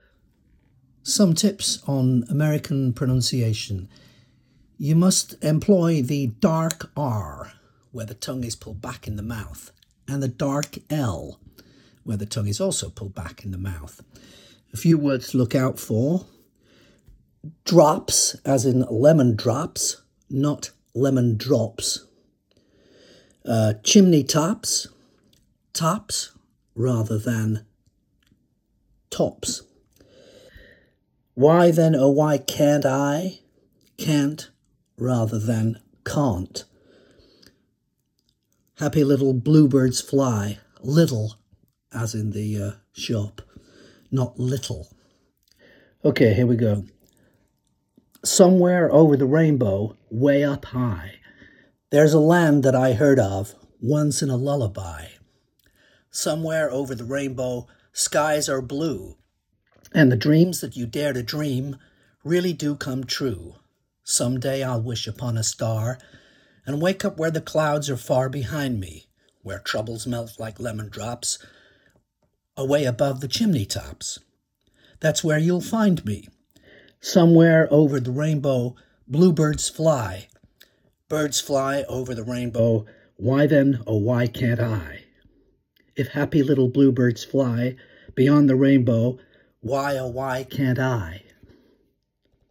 American pronunciation